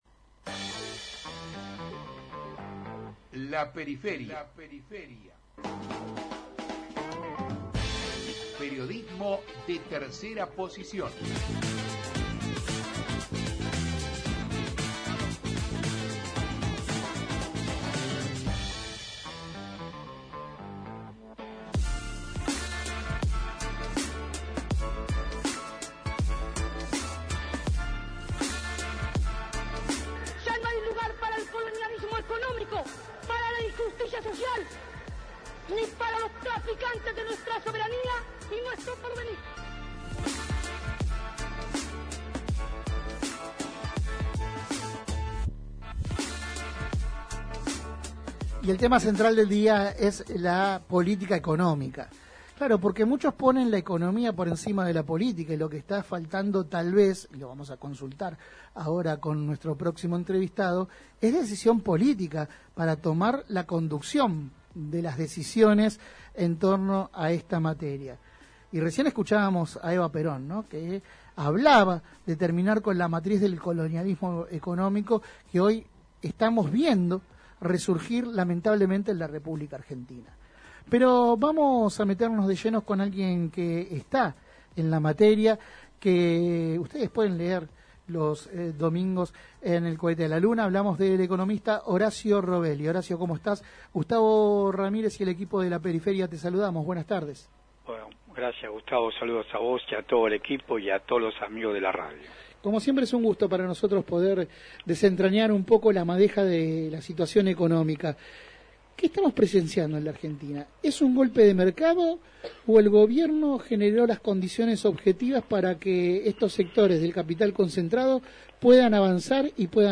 Compartimos la entrevista completa: […]